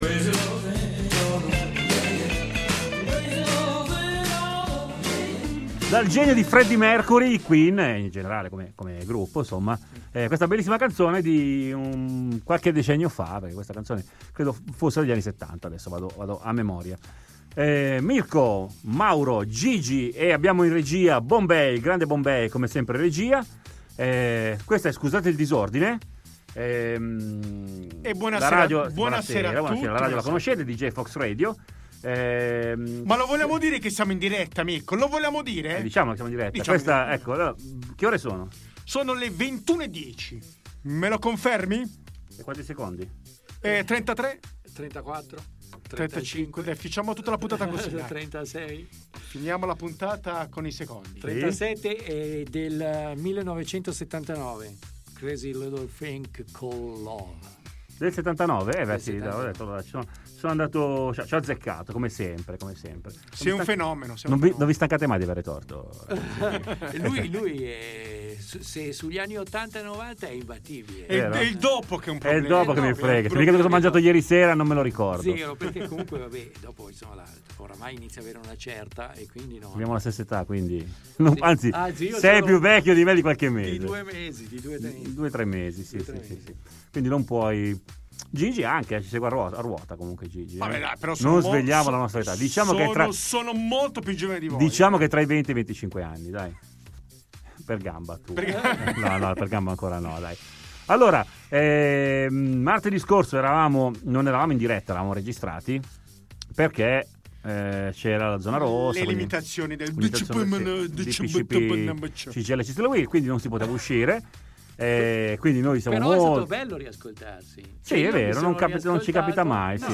Scusate il disordine è un programma radiofonico trasmesso dall'emittente radio web Deejay Fox Radio